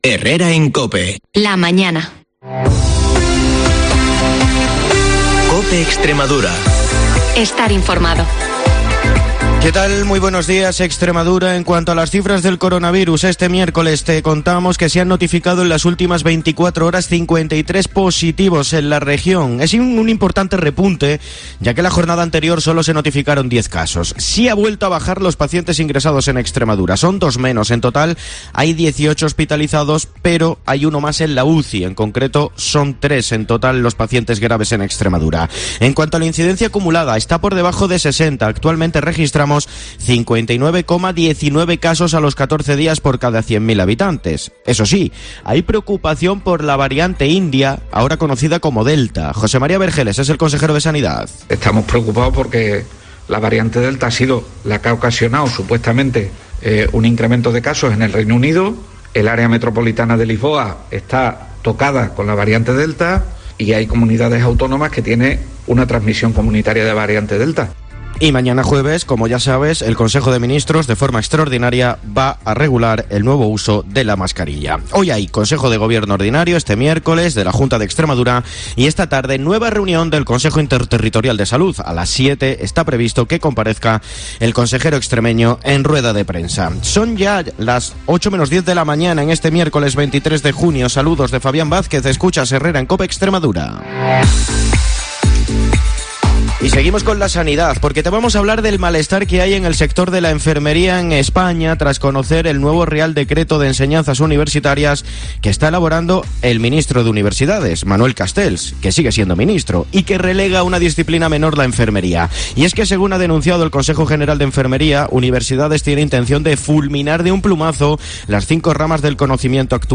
el informativo más escuchado de Extremadura